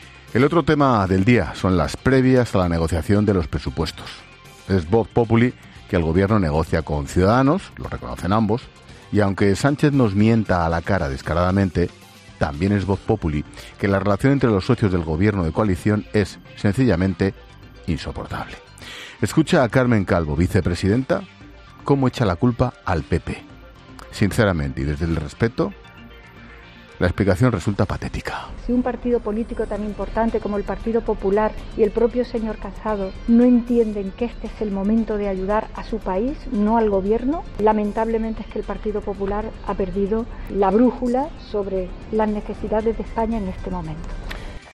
El presentador de 'La Linterna' ha reaccionado ante las declaraciones de la vicepresidenta para no pactar los presupuestos con el PP